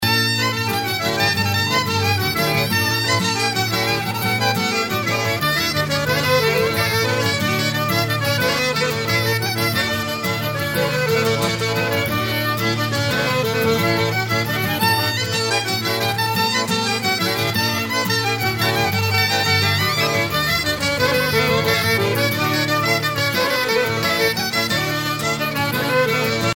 ridée 6 temps
Pièce musicale éditée